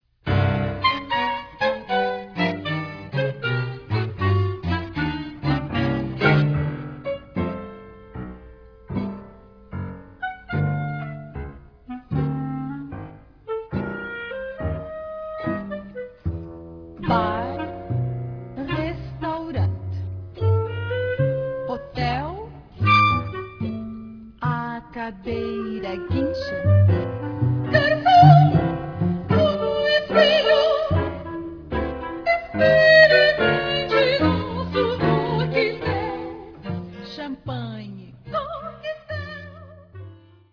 soprano
clarinete
violino
violoncelo
Um clarinete ilustra a frase "A cadeira guincha"